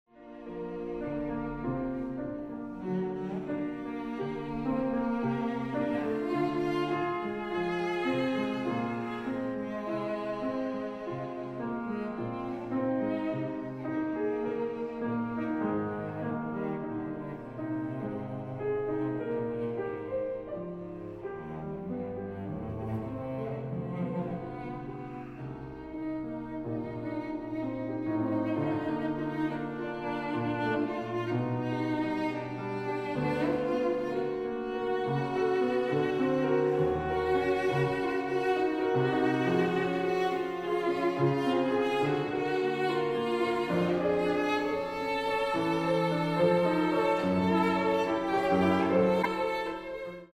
大提琴
鋼琴
使用骨董真空管麥克風錄音，並以自製的真空管混音台混音